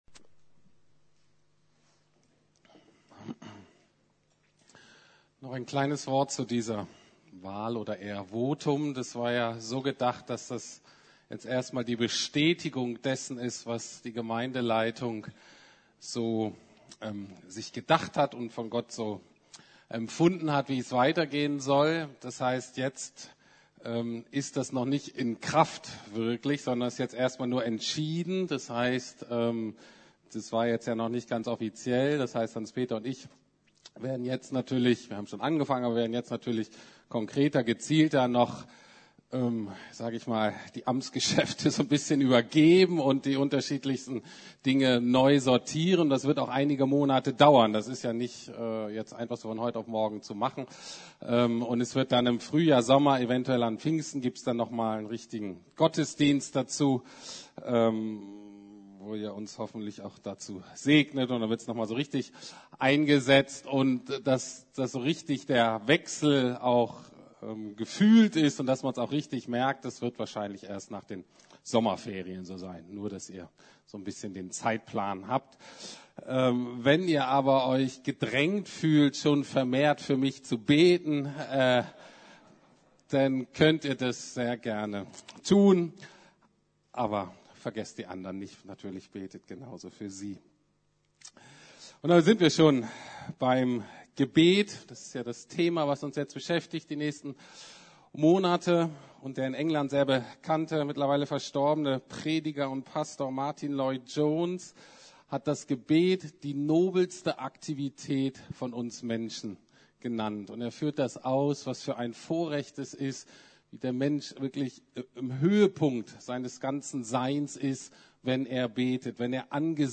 "Unser Vater im Himmel..." ~ Predigten der LUKAS GEMEINDE Podcast